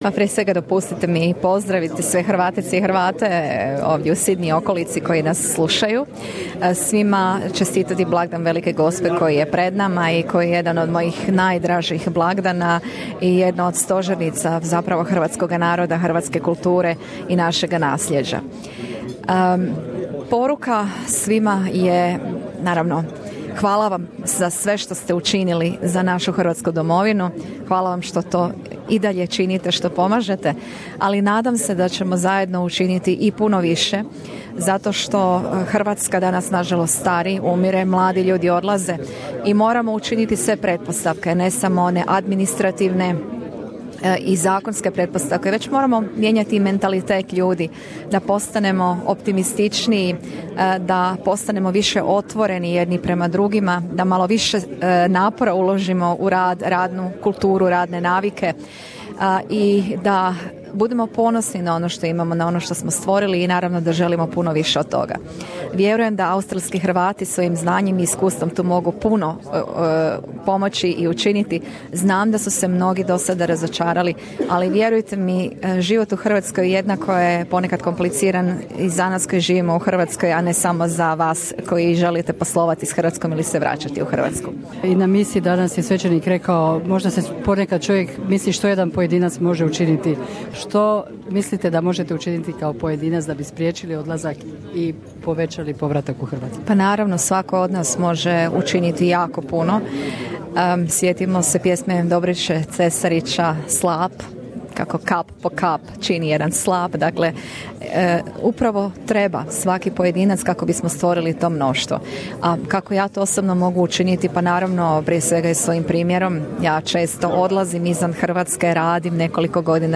Predsjednica Republike Hrvatske Kolinda Grabar-Kitarović u službenoj je posjeti Australiji. Prilikom posjete klubu Kralj Tomislav u Sydneju prvoga dana svoje posjete dala je izjavu za program na hrvatskom jeziku Radija SBS.